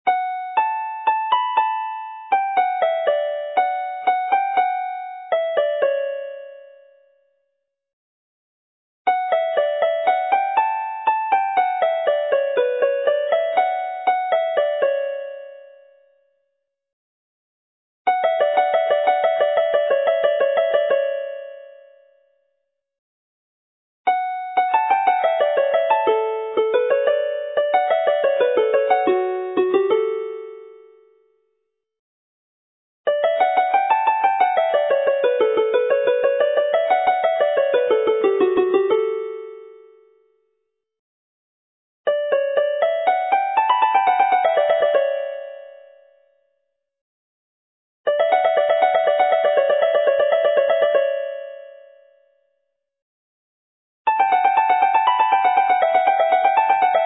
Addasiadau (dechreuad yn unig)
Improvisations - start of each to show pattern